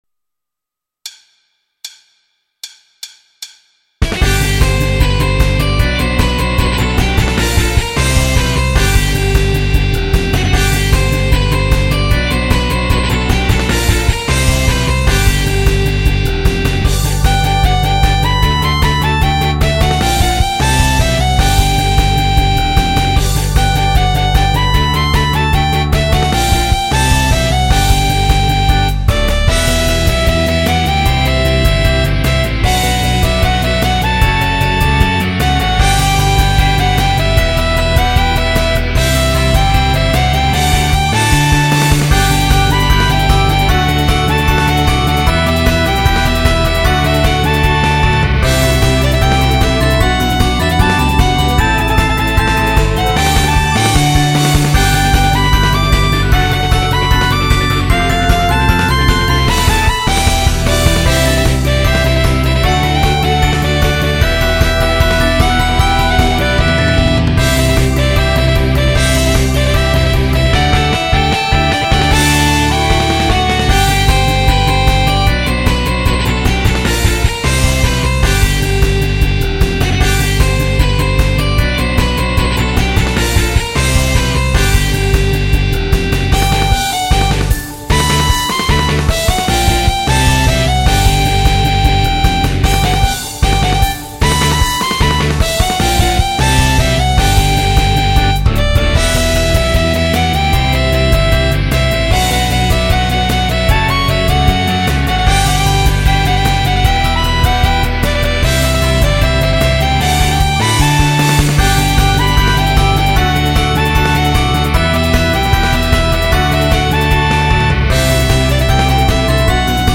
ライトロックポップ
ギター、ロックオルガン、Music Box
後半のギターソロ、ロックオルガンソロはかっこかわいく仕上がったと思います♪